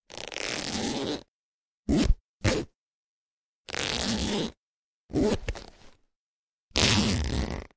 拉链 拉开拉链 - 声音 - 淘声网 - 免费音效素材资源|视频游戏配乐下载
一个大拉链的开合，在一个背包上。用Audition去掉了噪音假象，并进行了精简。